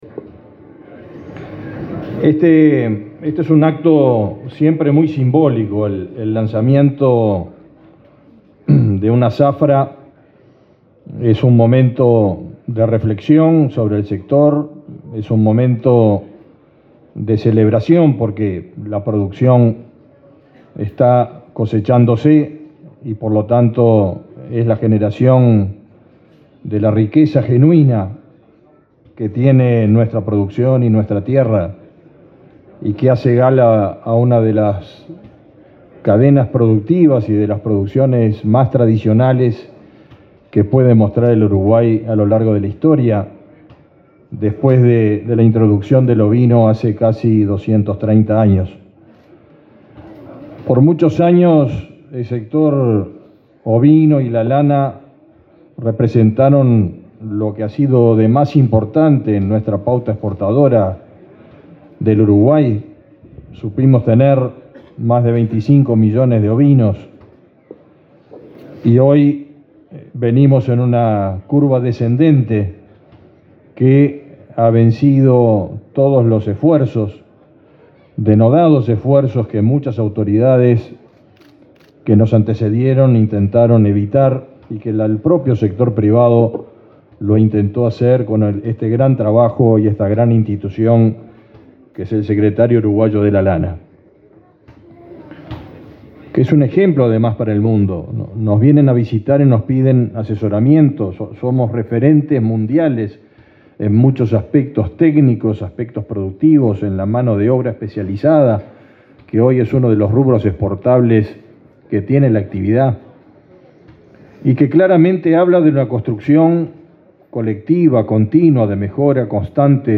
El ministro de Ganadería, Fernando Mattos, se expresó, este jueves 10 en Tacuarembó, durante el acto de lanzamiento de la zafra ovina 2023.